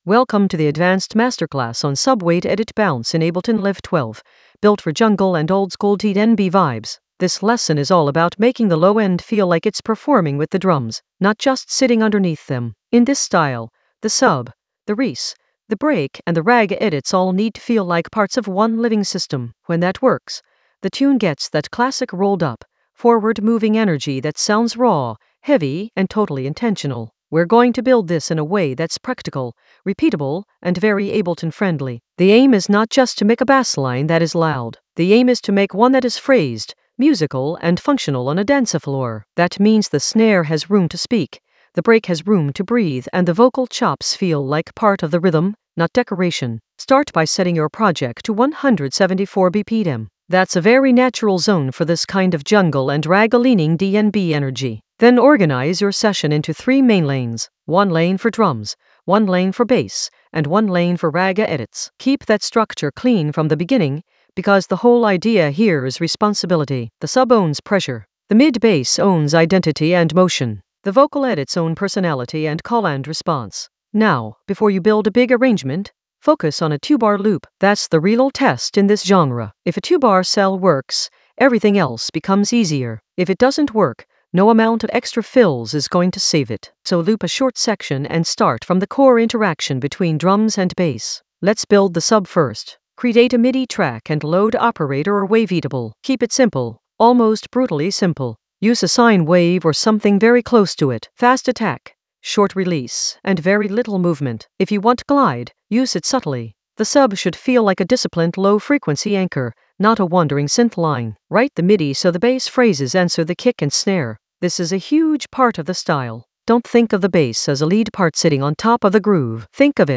Narrated lesson audio
The voice track includes the tutorial plus extra teacher commentary.
An AI-generated advanced Ableton lesson focused on Subweight masterclass: edit bounce in Ableton Live 12 for jungle oldskool DnB vibes in the Ragga Elements area of drum and bass production.